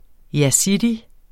Udtale [ jaˈsidi ]